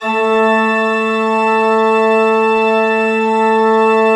Index of /90_sSampleCDs/Propeller Island - Cathedral Organ/Partition I/MAN.PLENO R